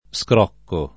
vai all'elenco alfabetico delle voci ingrandisci il carattere 100% rimpicciolisci il carattere stampa invia tramite posta elettronica codividi su Facebook scrocco [ S kr 0 kko ] s. m. («lo scroccare»; «scatto»); pl.